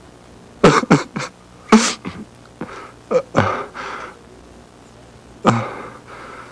Crying (male) Sound Effect
Download a high-quality crying (male) sound effect.
crying-male-2.mp3